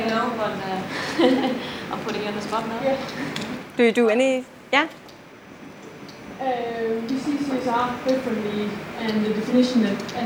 I video taped a public speaker who was wearing a microport.
I think that the best you will be able to do is to “duck” between the channels - that is, when the main speaker is talking, fade out the shotgun mic and when the person in the audience speaks, fade out the microport. You will end up with something like this, which though not ideal is comprehensible.